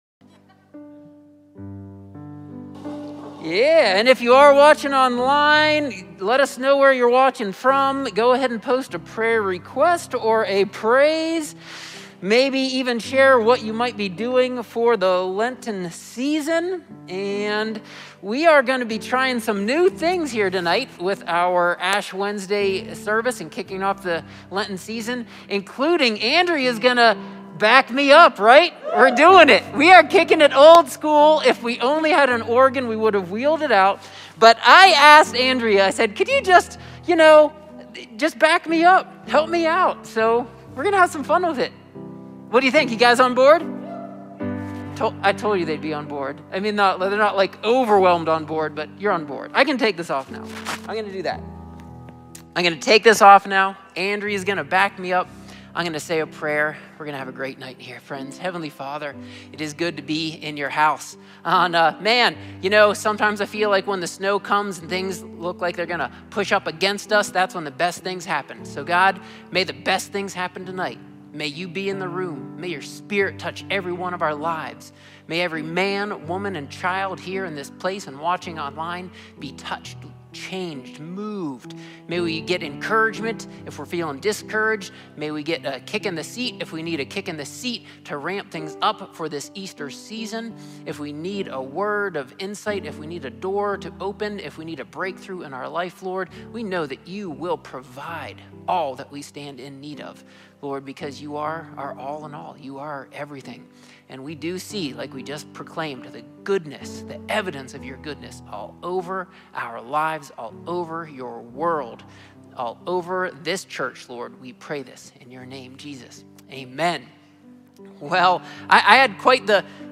A sermon from the series "The Psalms."